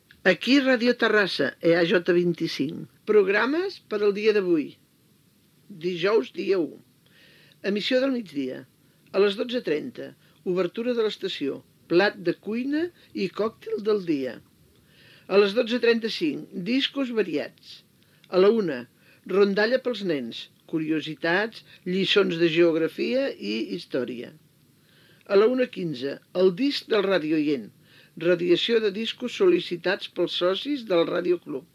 Identificació, data i programació del dia (recreació posterior)